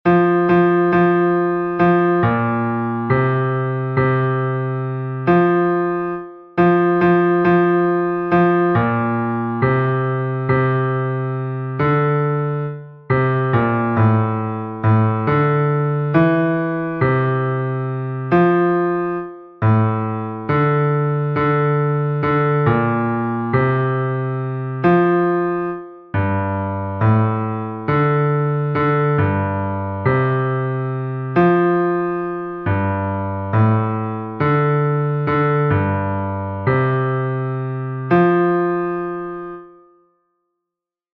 Fichier son basse 2